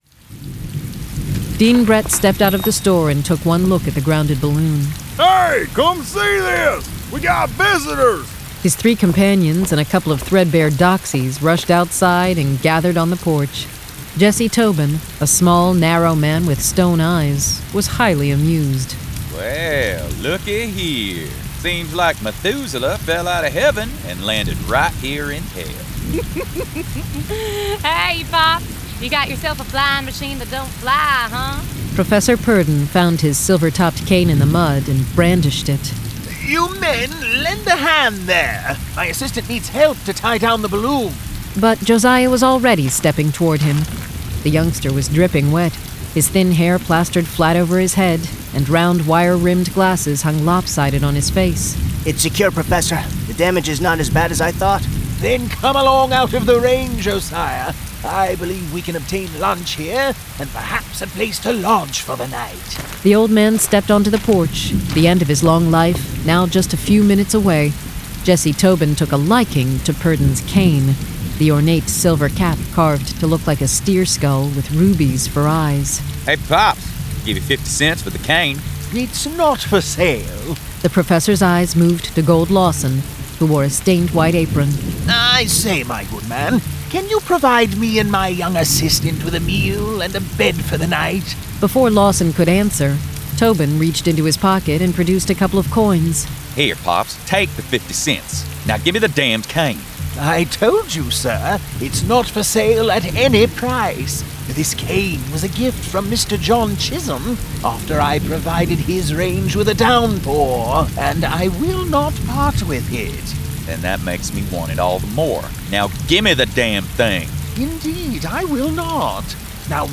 The Kerrigans 4: Hate Thy Neighbor [Dramatized Adaptation]
Full Cast. Cinematic Music. Sound Effects.
Genre: Western